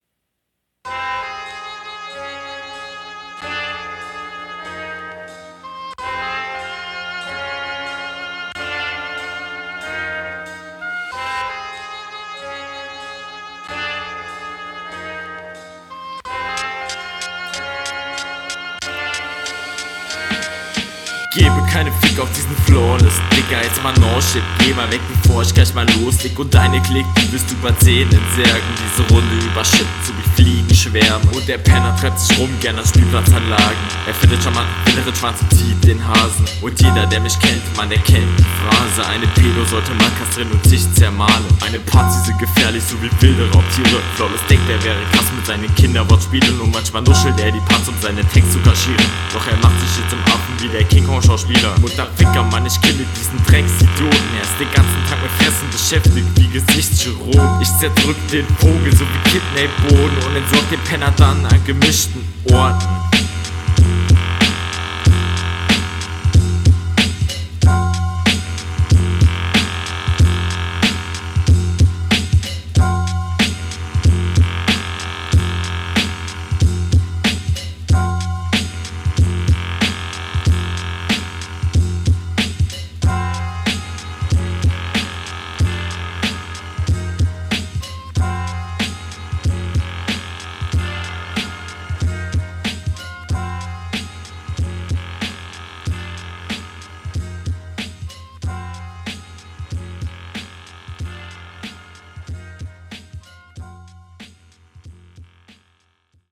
Flowlich besser als in Runde 1.
nuscheln fronten schwierig bei dir aber der beat ist geil